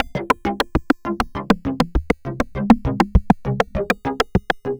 tx_synth_100_disguised_C.wav